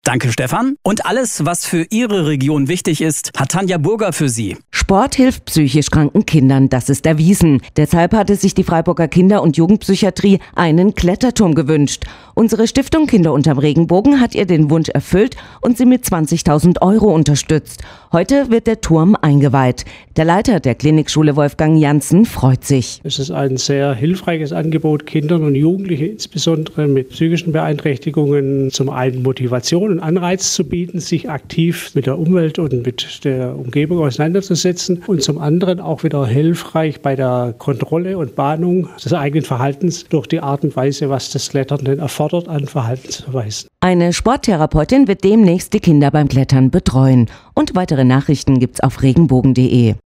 Sendemitschnitt Teil 1 und Teil 2: